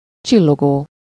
Ääntäminen
Synonyymit brillant scintillant coruscant flamboyant Ääntäminen France: IPA: [e.tɛ̃.slɑ̃] Tuntematon aksentti: IPA: /e.tɛ̃.sə.lɑ̃/ Haettu sana löytyi näillä lähdekielillä: ranska Käännös Ääninäyte 1. csillogó Suku: m .